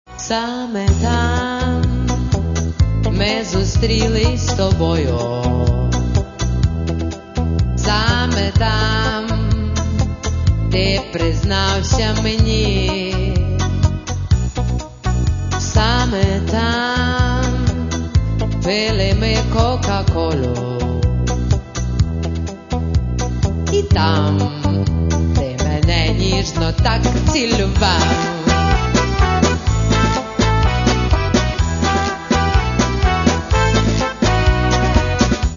Каталог -> Поп (Легкая) -> Юмор
Легкая и энергичная музыка, шутка в каждой строке.